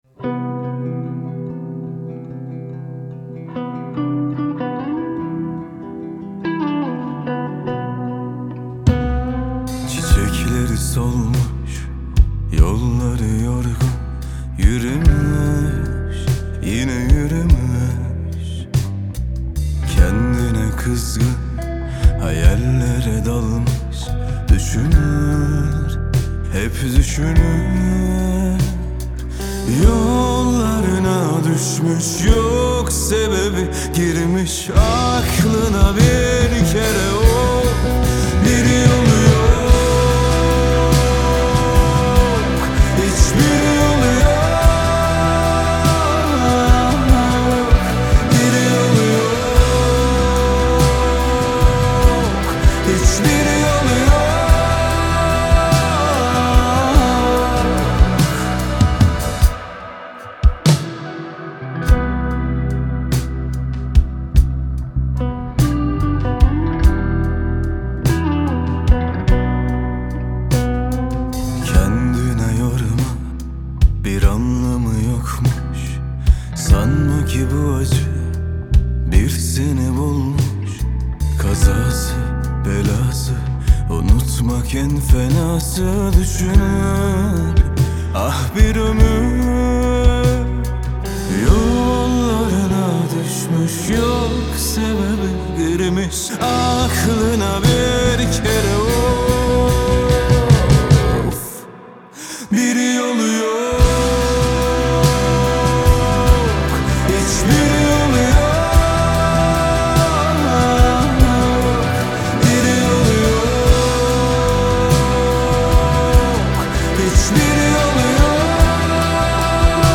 Трек размещён в разделе Турецкая музыка / Рок.